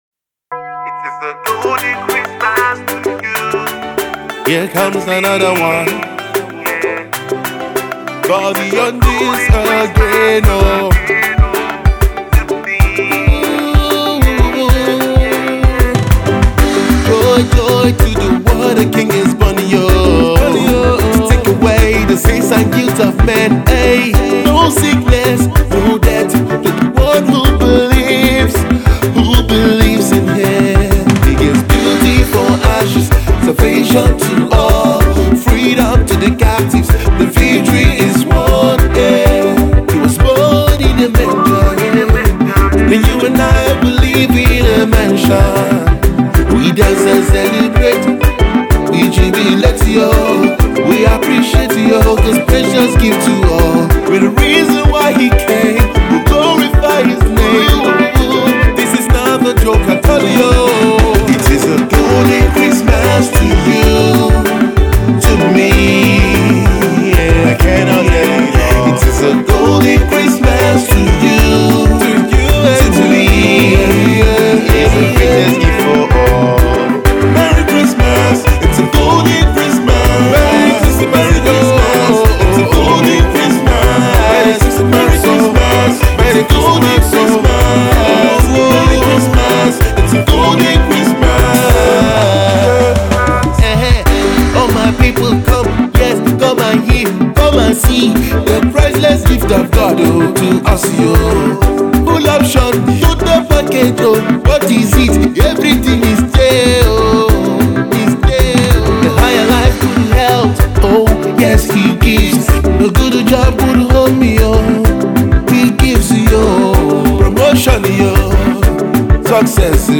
fussed with african
tunes and melodies will make you merry .